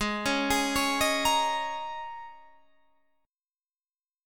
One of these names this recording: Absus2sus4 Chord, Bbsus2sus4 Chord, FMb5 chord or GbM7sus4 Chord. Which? Absus2sus4 Chord